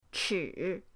chi3.mp3